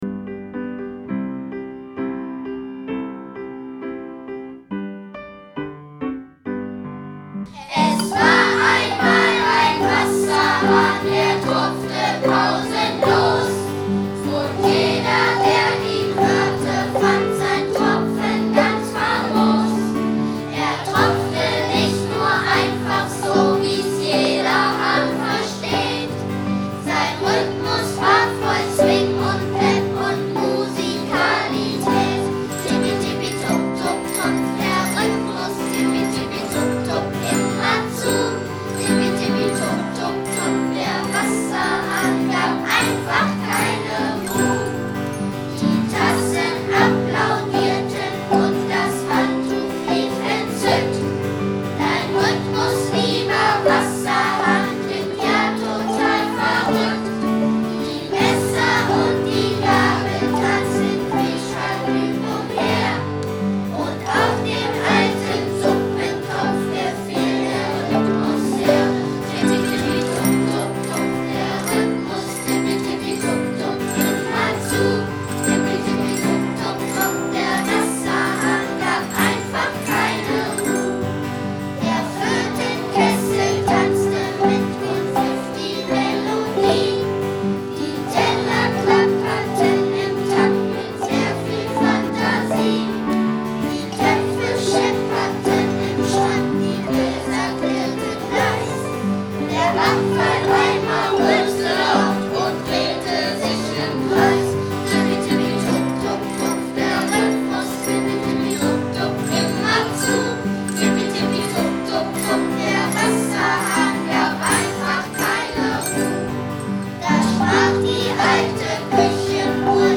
Xylophone, Metallophone und Glockenspiele
Verwandt wurde also kein Playback - es handelt sich ausschließlich um Live-Musik!